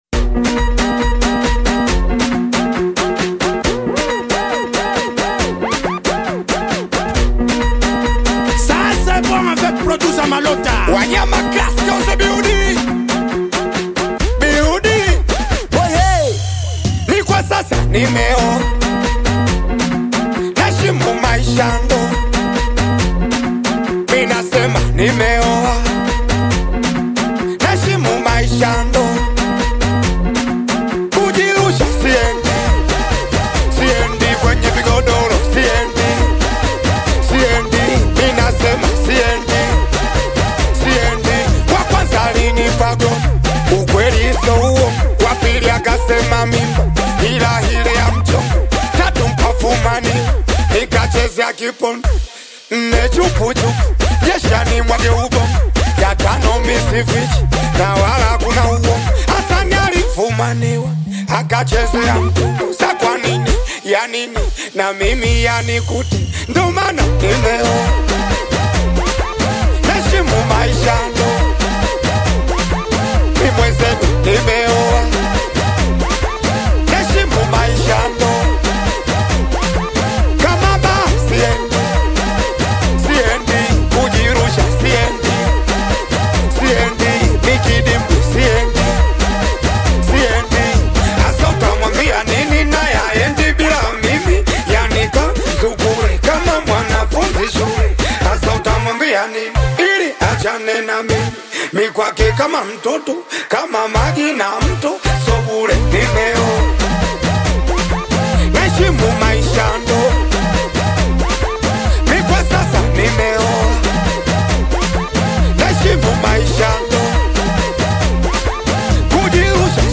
Singeli music track
Singeli song